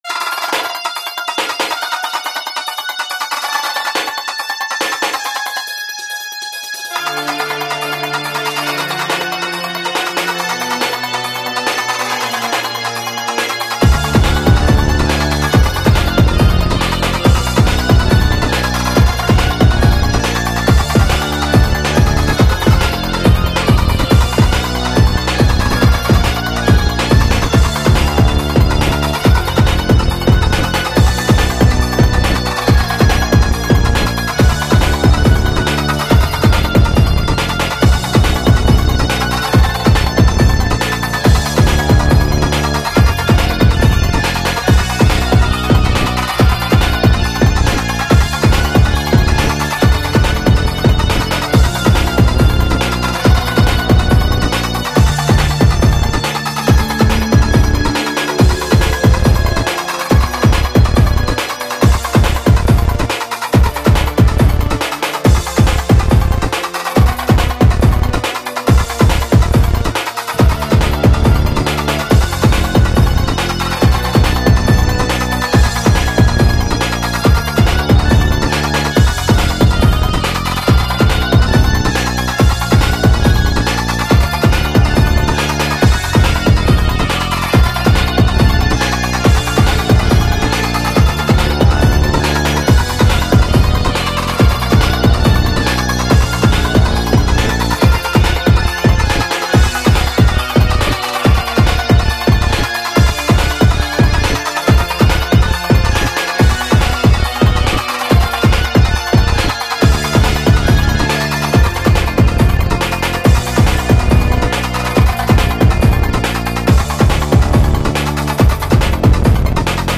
ReMix
3:53 min [140 BPM]